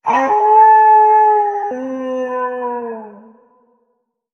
Здесь вы найдете реалистичные аудиозаписи воя, рычания и других эффектов, связанных с этими легендарными существами.
Вой оборотня-чудовища